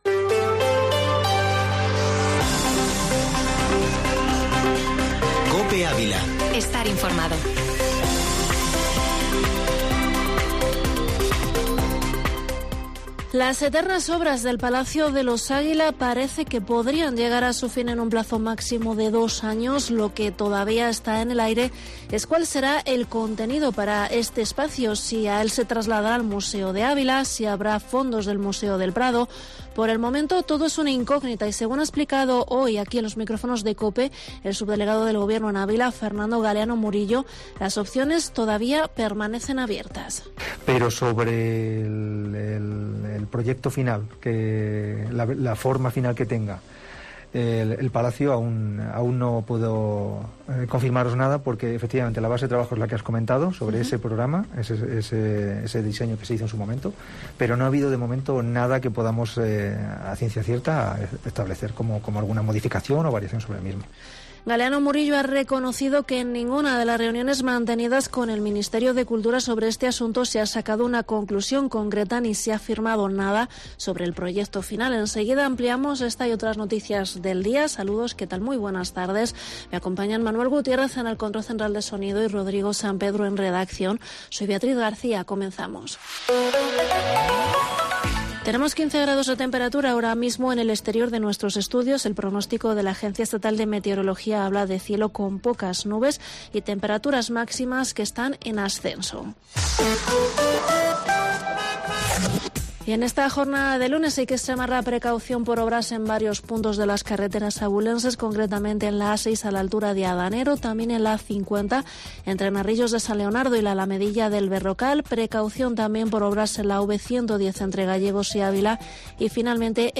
Informativo Mediodía Cope en Avila 25/4/2022